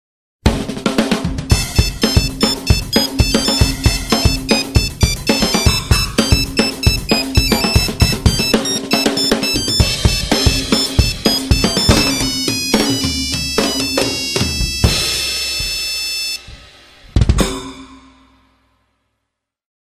cool-drums-mix_23509.mp3